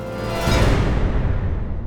转场.wav